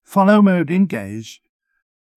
follow-mode-engaged.wav